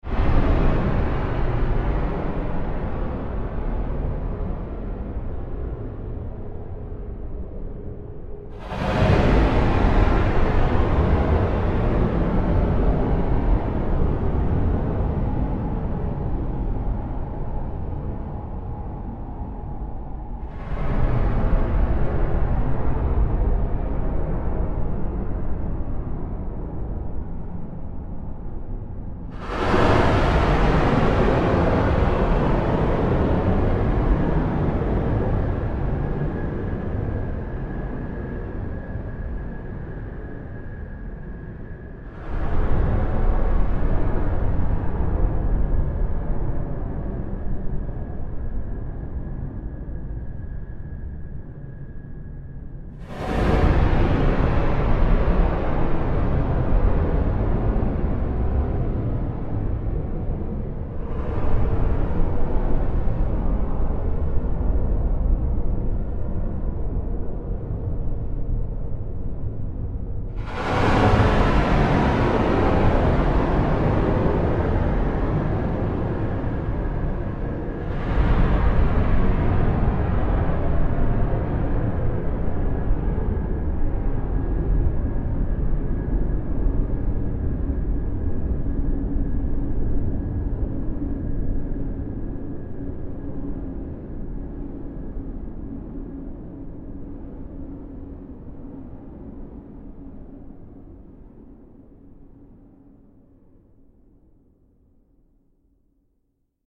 Extra Long Sound Effect - 1m 46s
Use This Extra Long Premium Hollywood Studio Quality Sound In Stereo.
Channels: 2 (Stereo)
This Premium Quality Futuristic Sound Effect
Tags: long large alien beam beams future futuristic laser sci-fi science fiction scifi